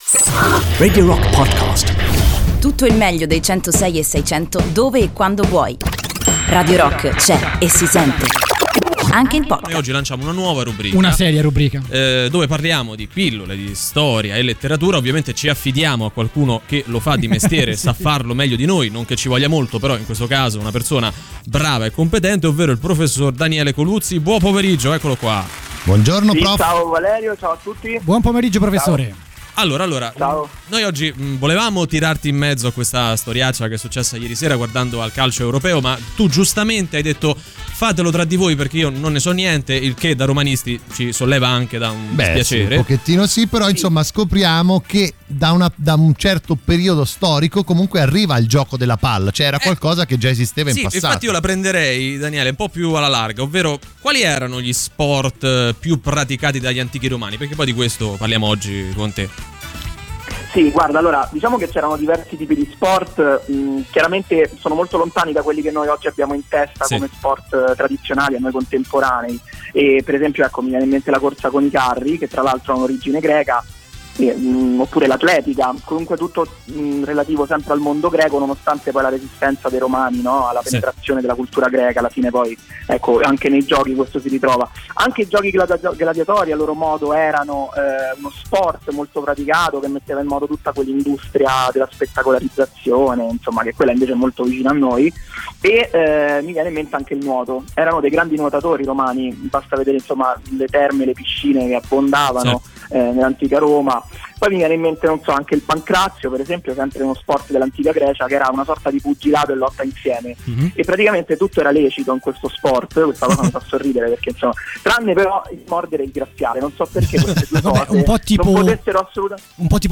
interviene in diretta su Radio Rock